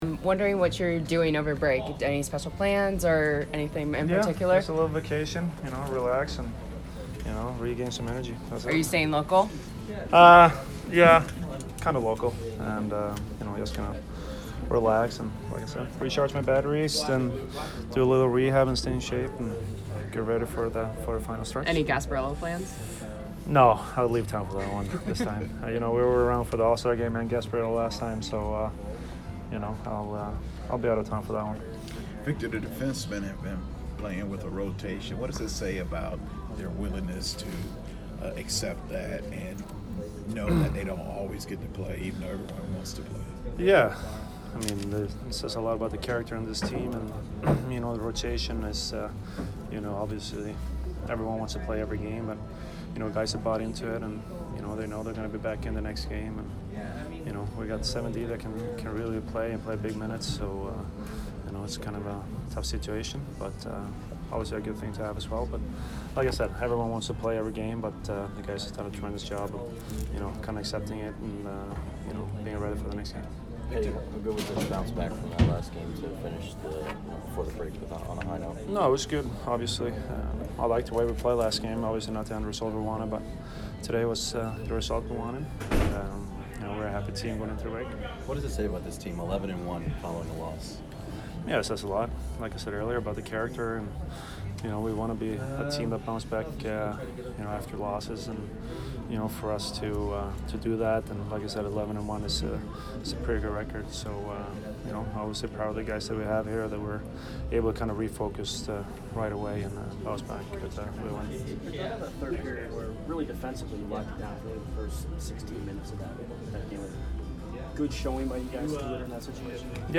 Victor Hedman post-game 1/19